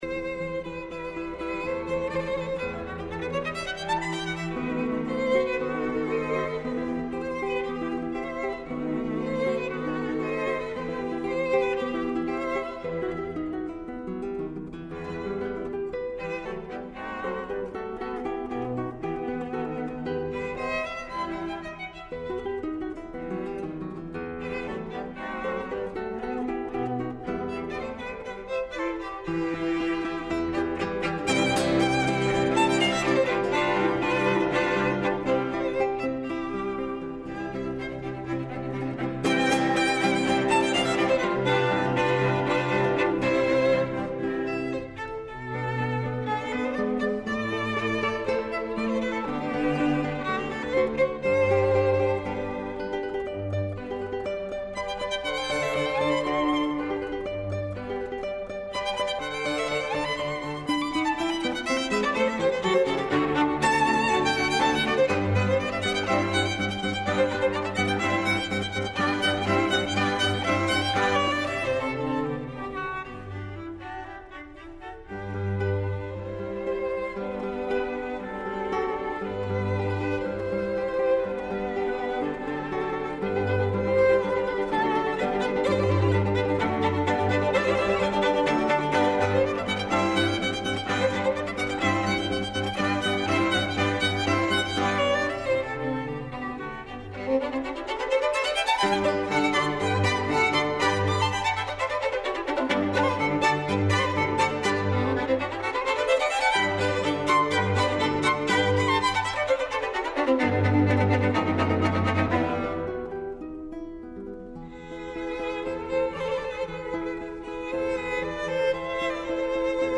квинтетима за гитару и гудачки квартет